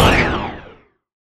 arcaneimpact.wav